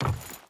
Wood Chain Run 4.ogg